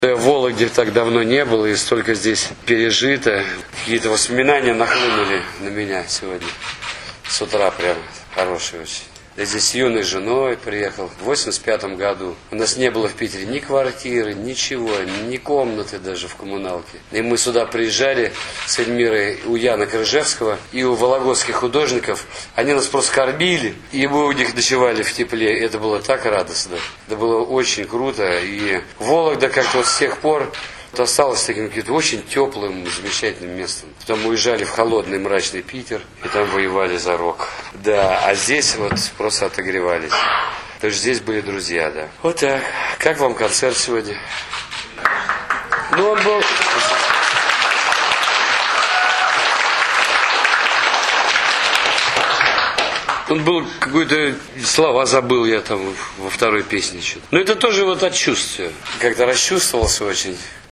На встрече с журналистами Юрий Юлианович отметил, что так как Вологде, его нигде раньше не встречали.